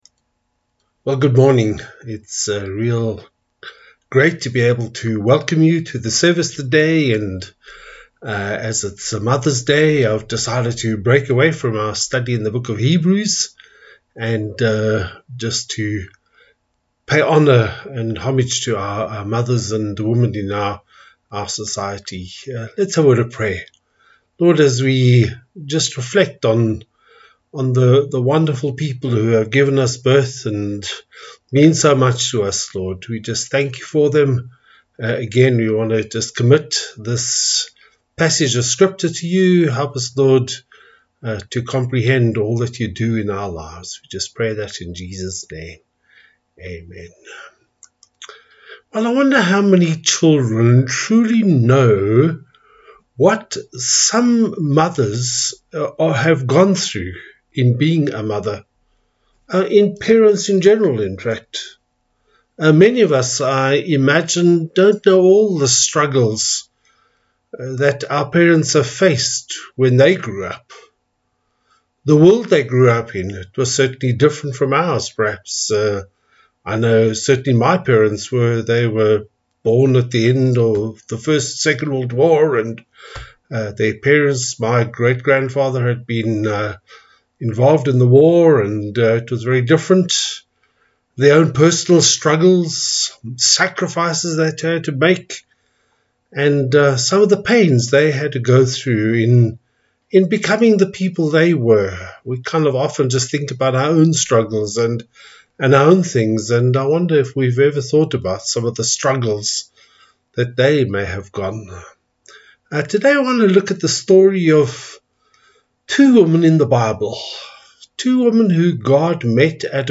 Passage: Genesis 21:8-21; John 4:1-26 Service Type: Sunday Service